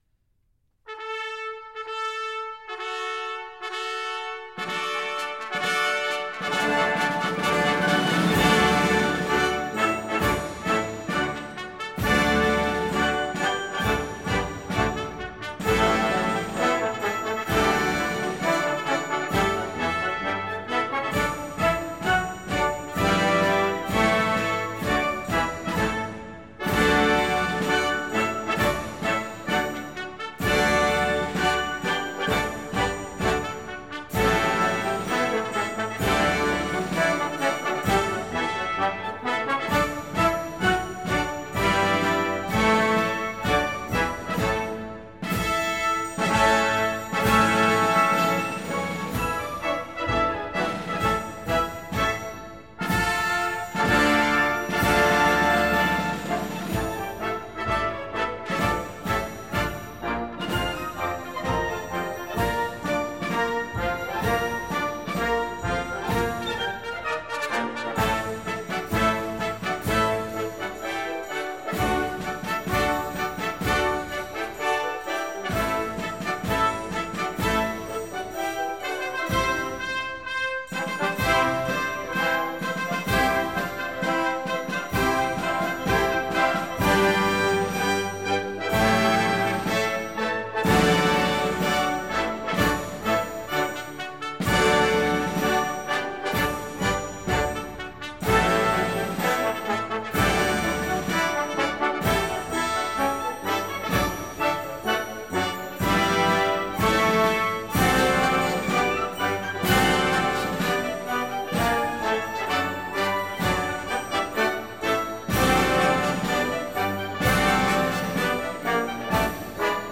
Marching Band  (View more Intermediate Marching Band Music)
Classical (View more Classical Marching Band Music)
march-for-military-band-woo-24.mp3